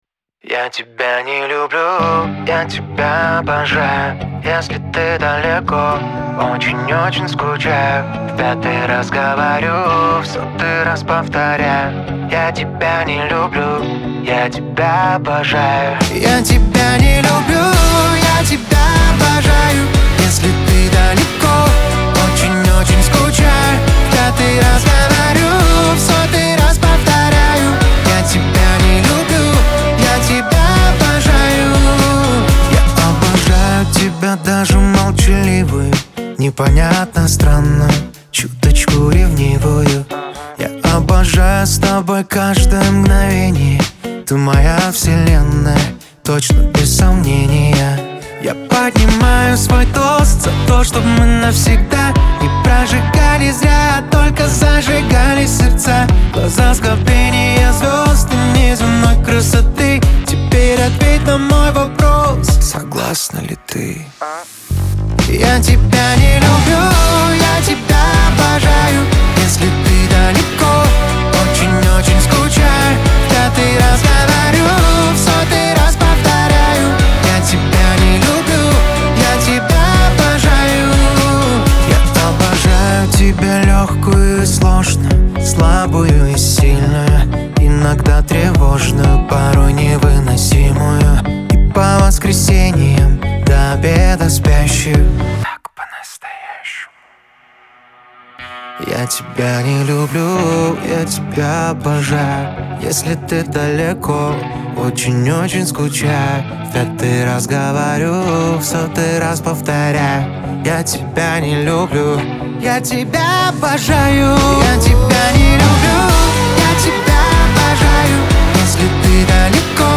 эстрада , pop